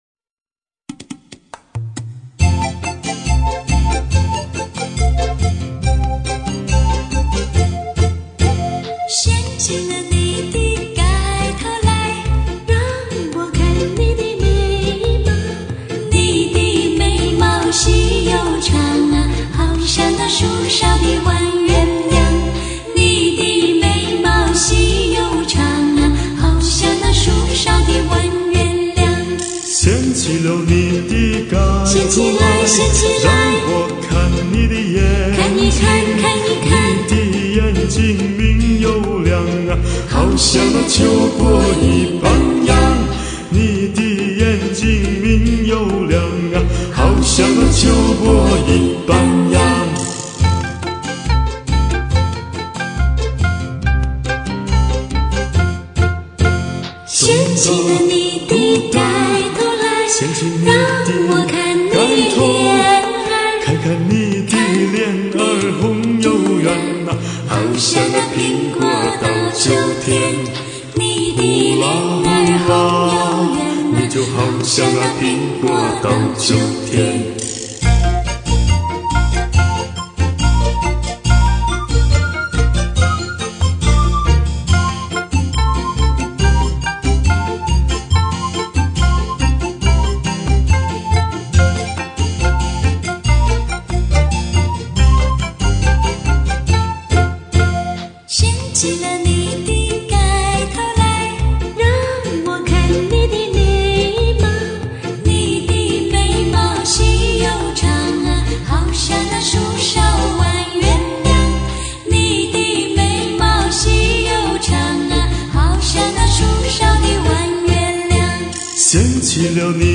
类别：流行